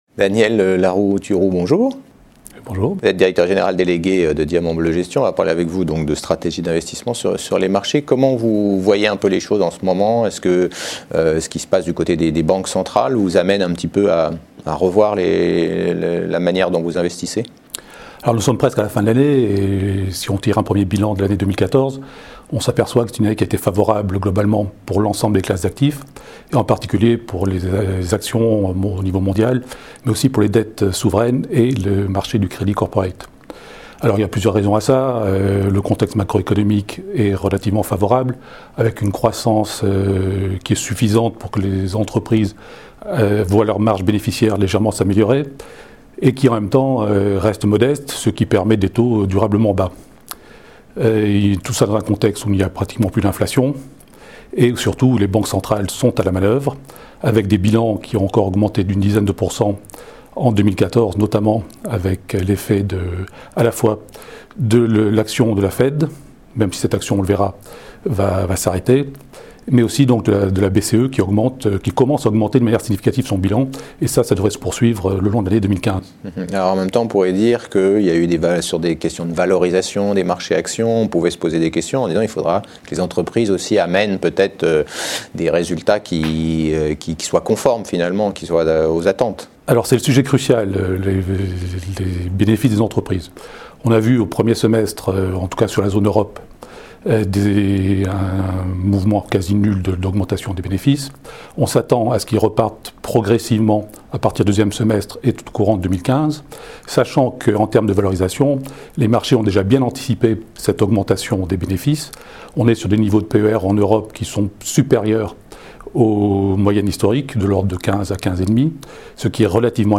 Mon invité est